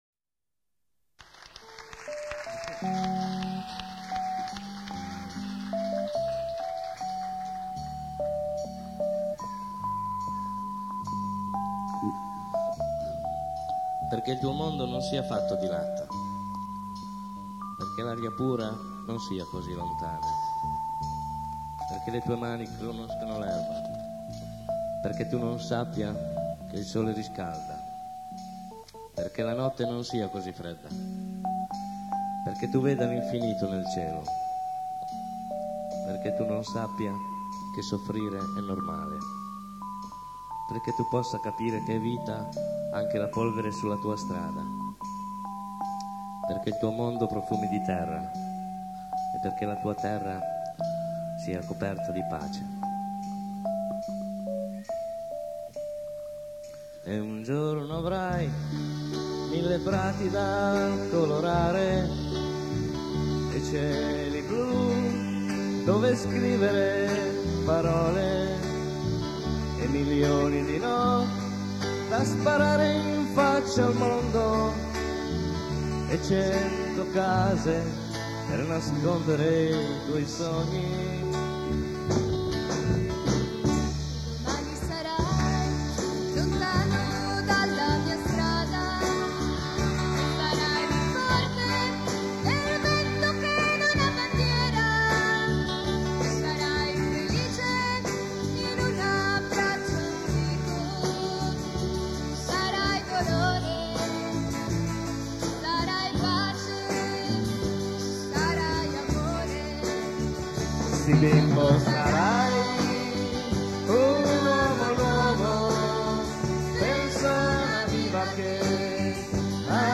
Download   CD live (1983-1993) del 1998